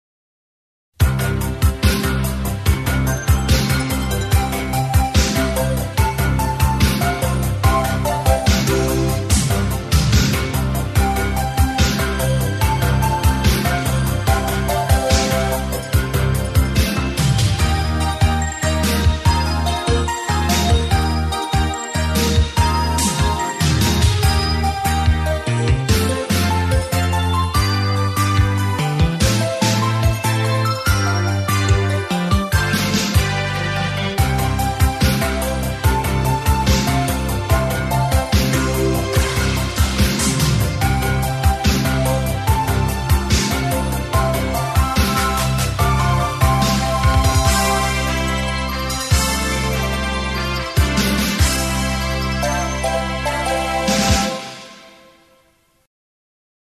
Sintonia de l'emissora